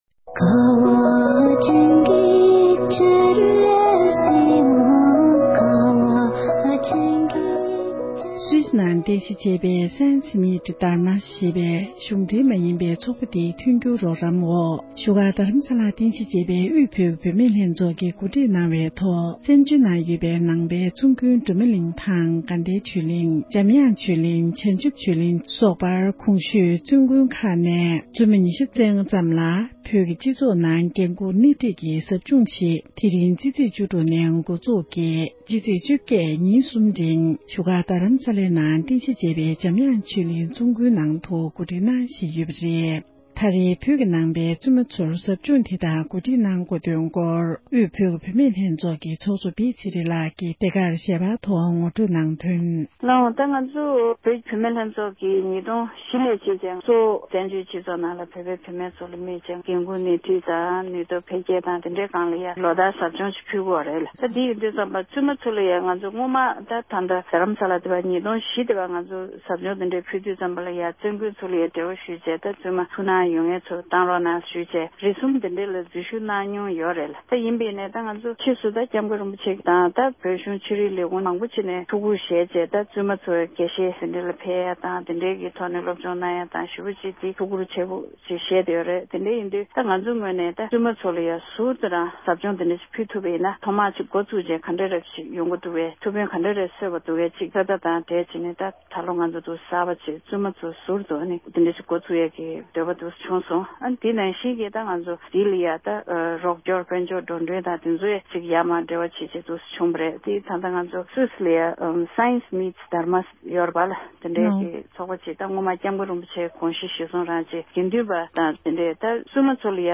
འབྲེལ་ཡོད་མི་སྣར་འནས་འདྲི་ཞུས་ཏེ་ཕྱོགས་བསྒྲིགས་ཞུས་པར་གསན་རོགས་ཞུ༎